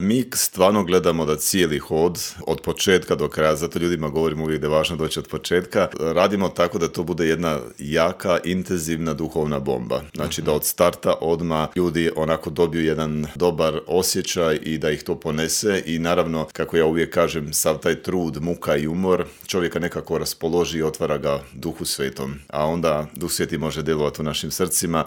Cijeli intervju možete pronaći na YouTube kanalu Media servisa.